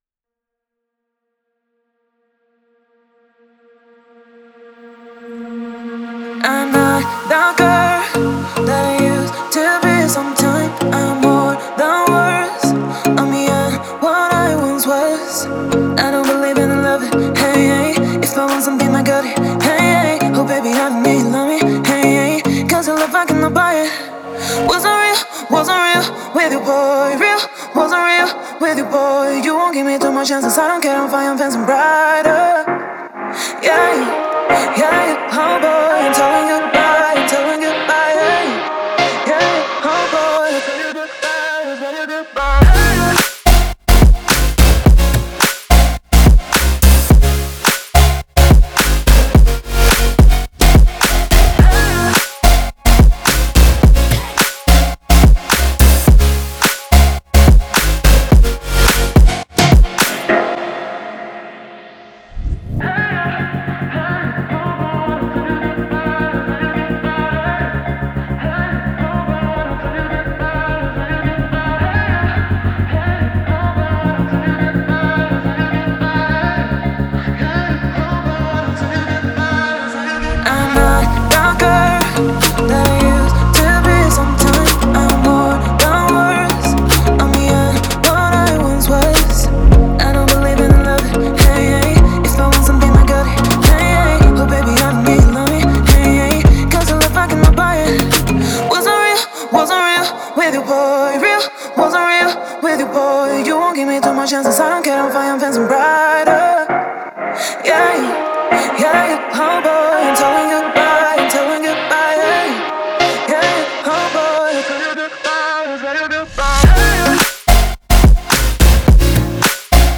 Electronic, Happy, Romantic, Dreamy, Euphoric